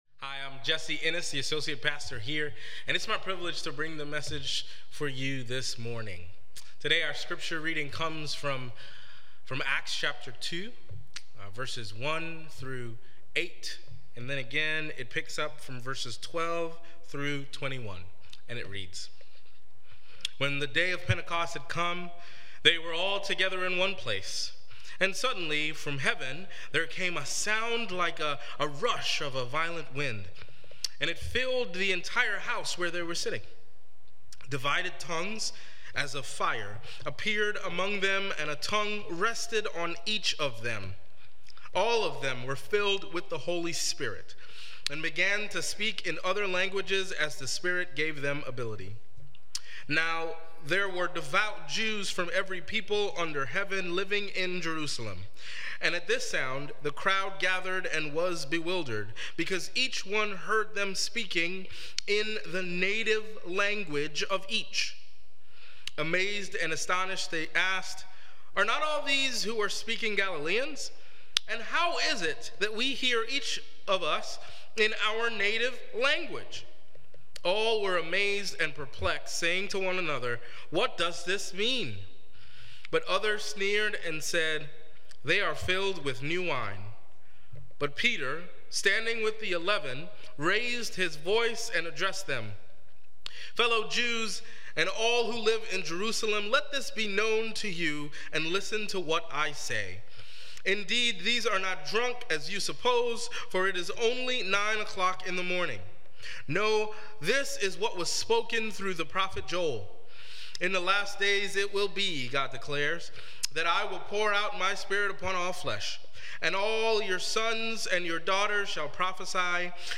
Sermon Reflections: How does the confusion of languages at the Tower of Babel compare to the experience of the disciples and the crowd at Pentecost?